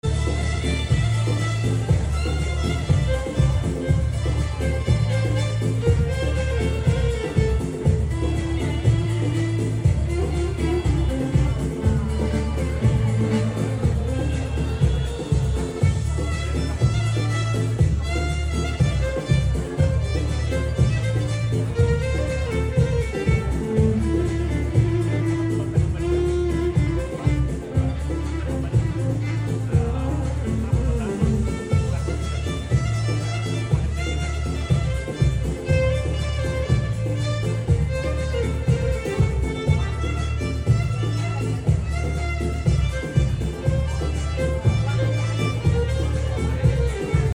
عزف كمان